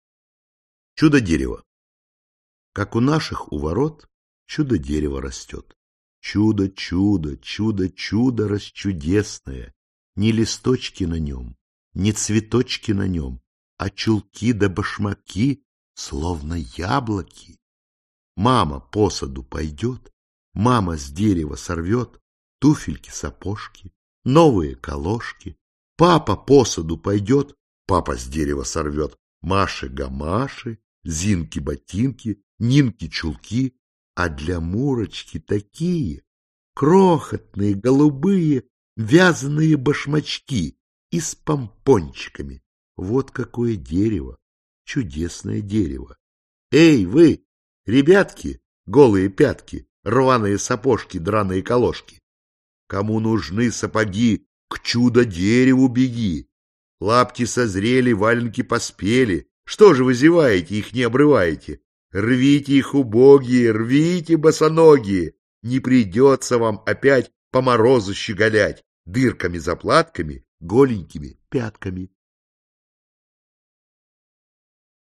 Аудиокнига Айболит и другие | Библиотека аудиокниг
Aудиокнига Айболит и другие Автор Корней Чуковский Читает аудиокнигу Сергей Гармаш.